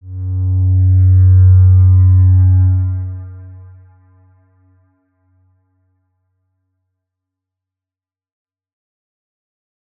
X_Windwistle-F#1-mf.wav